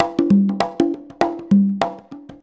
100 CONGAS09.wav